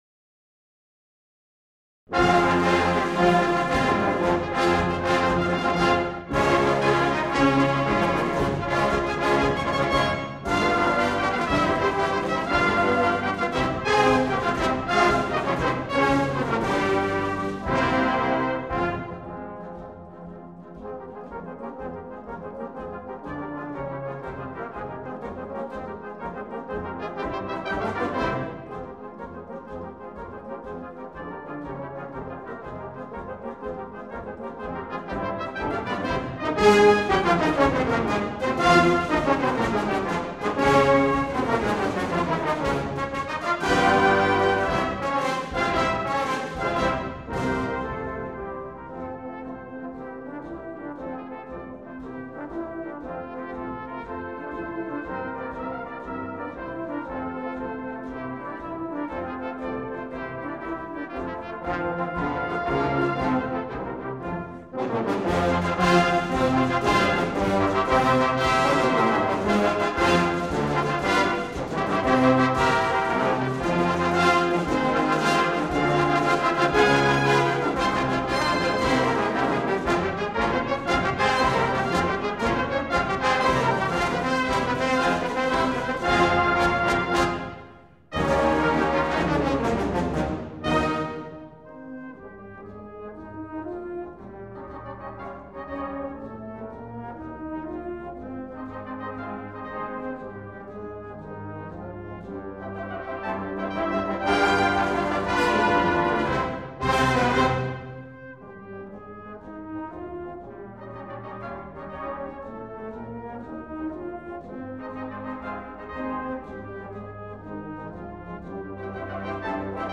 recorded at Cartmel School